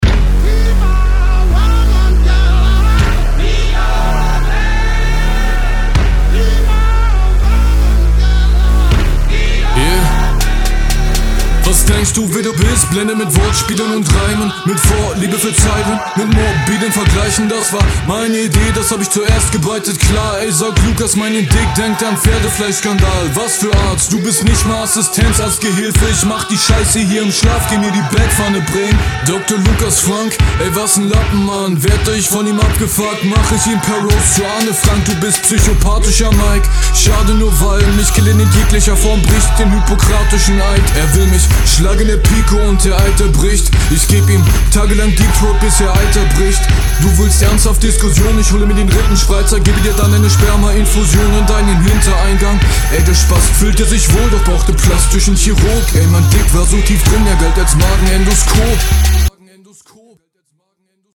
Flow: Sehr druckvoll, schöne schuffles und Stimme passt auch gut in den Beat hinein.
Sehr experimenteller Beatpick!